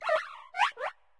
Cri de Pohmotte dans Pokémon Écarlate et Violet.